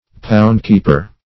Poundkeeper \Pound"keep`er\, n. The keeper of a pound.